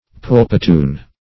Search Result for " pulpatoon" : The Collaborative International Dictionary of English v.0.48: Pulpatoon \Pul`pa*toon"\, n. [F. poulpeton, poupeton, a sort of ragout.] A kind of delicate confectionery or cake, perhaps made from the pulp of fruit.
pulpatoon.mp3